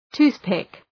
Προφορά
{‘tu:ɵpık}